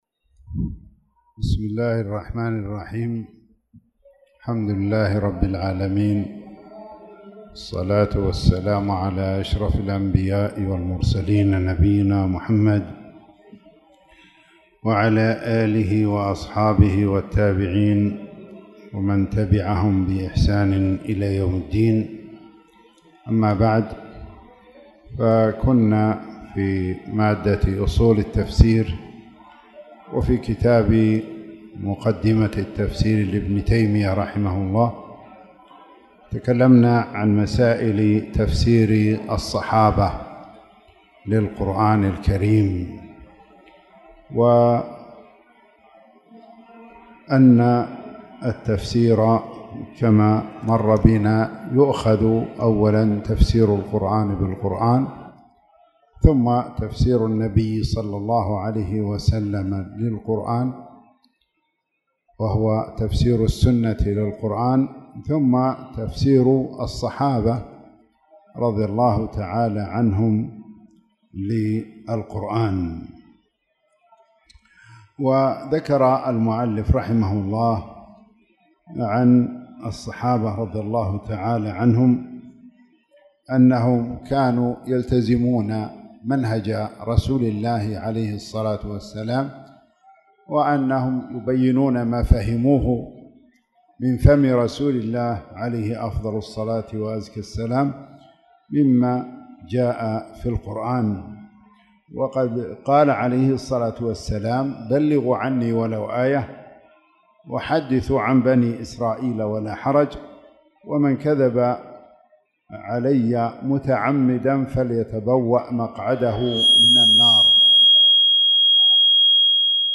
تاريخ النشر ١٩ شوال ١٤٣٧ هـ المكان: المسجد الحرام الشيخ